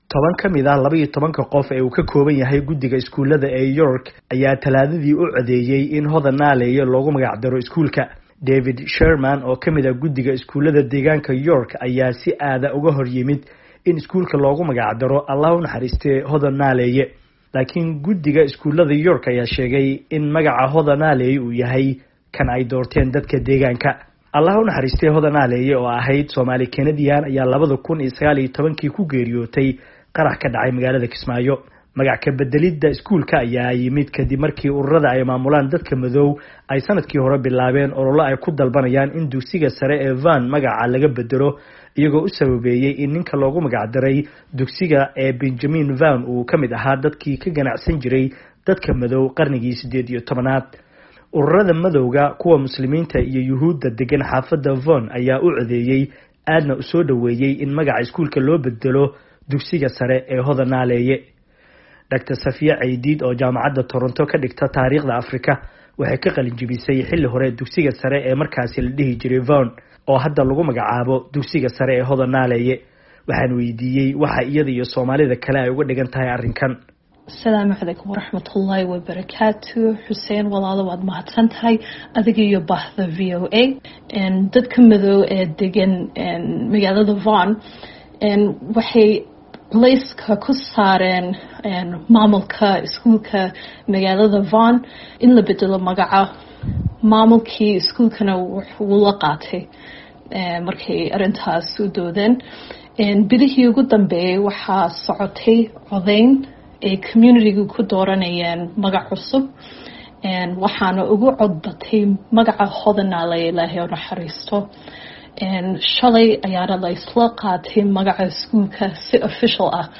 Halkaan ka dhagayso warbixinta Dugsiga